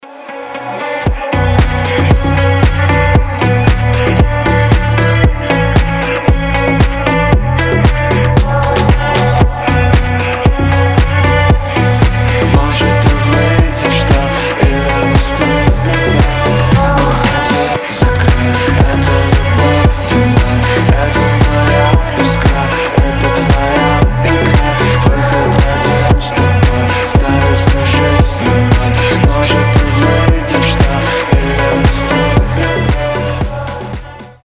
• Качество: 320, Stereo
deep house
атмосферные
женский голос
дуэт